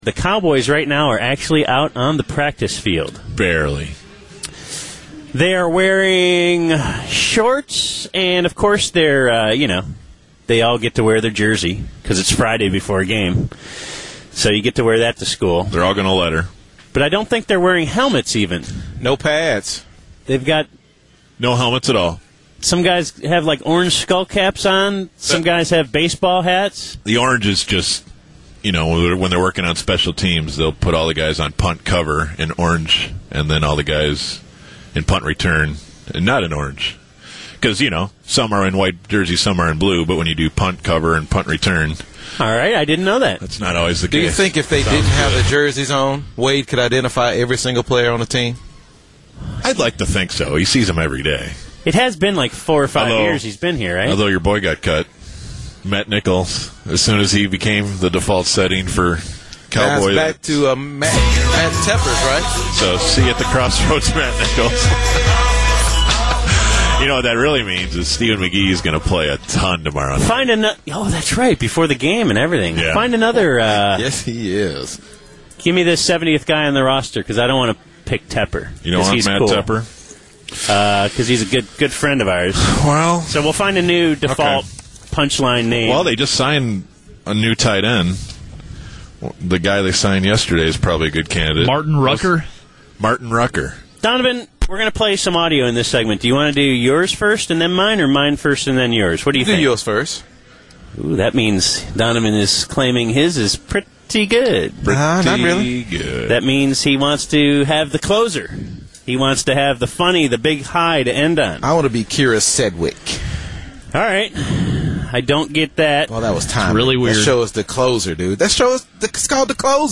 BaD Radio took the wireless microphone out on their last day in Oxnard to mine audio gold.